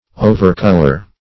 Overcolor \O`ver*col"or\